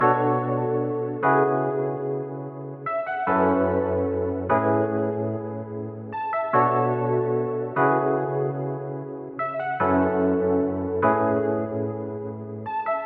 波浪形的罗德斯
描述：复古的罗德斯
Tag: 147 bpm Trap Loops Rhodes Piano Loops 2.20 MB wav Key : Unknown FL Studio